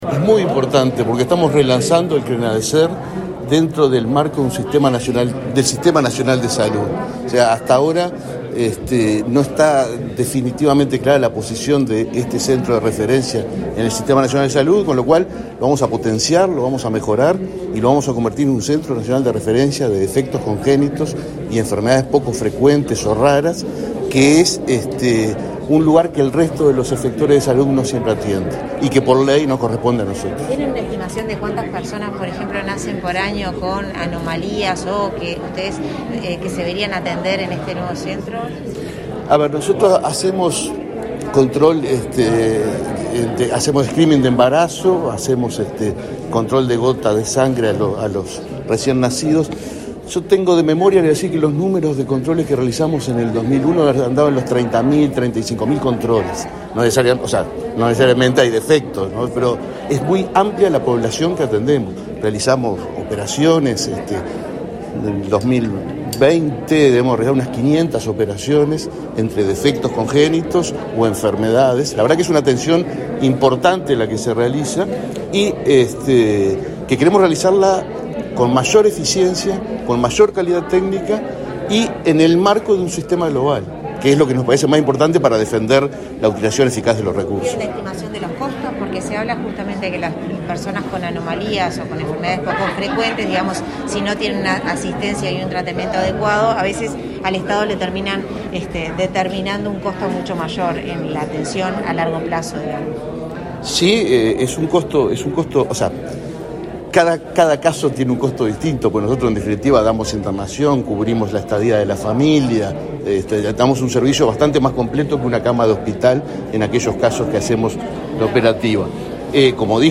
Declaraciones del presidente del BPS, Alfredo Cabrera, a la prensa